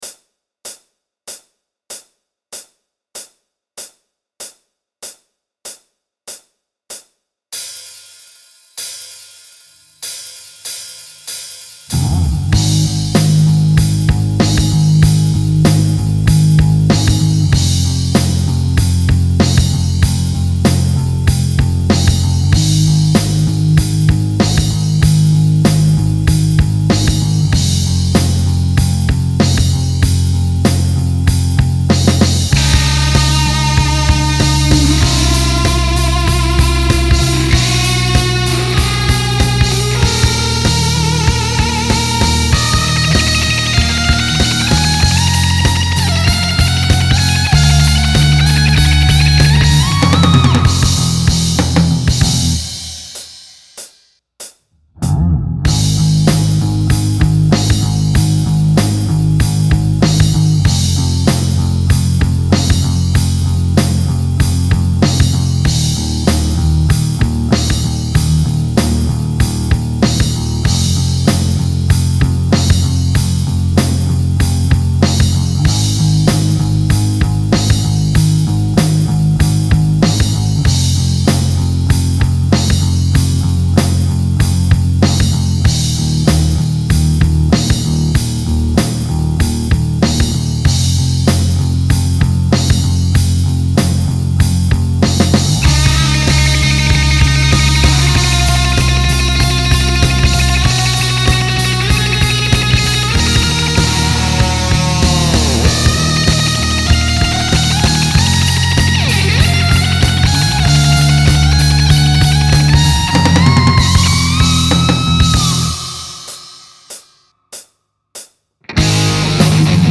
You be the main guitar part